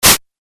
シンセ 8bit 攻撃 noise
ドッ